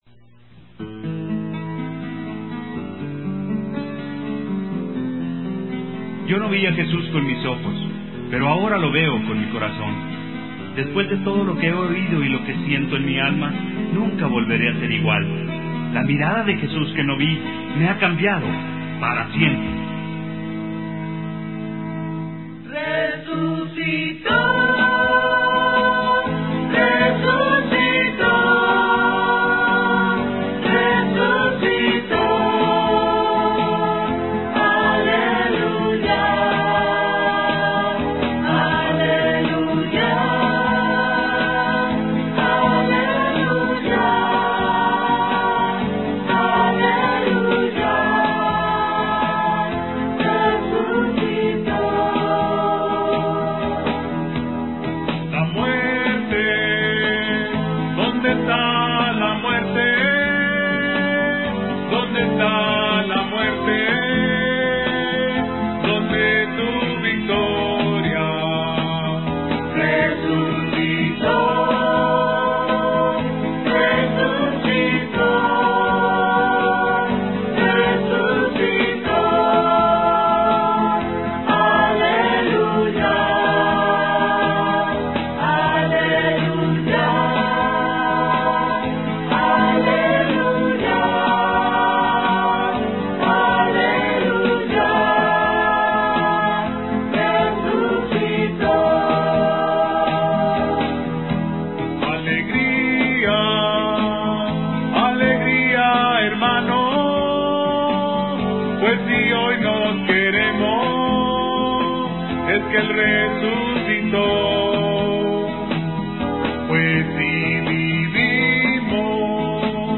(Contralto)
(Tenor)